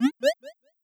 Modern UI SFX / AlertsAndNotifications
Success1.wav